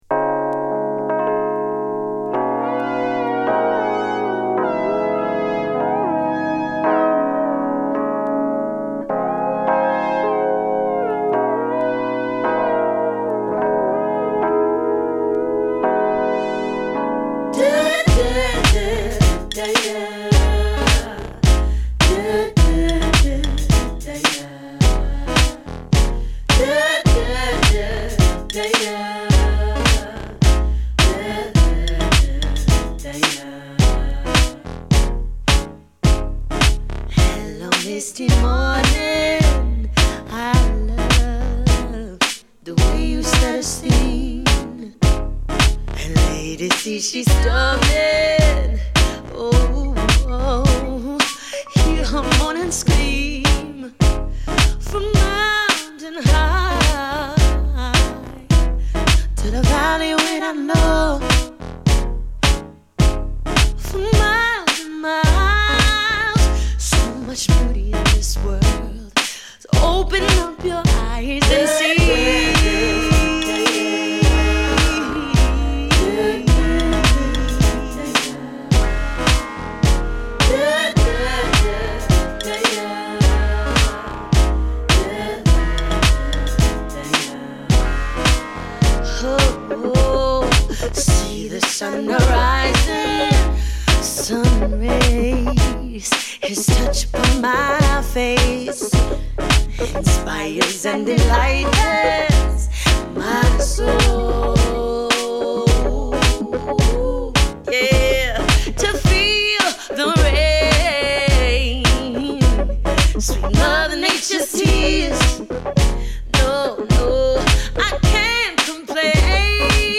サックスプレイヤー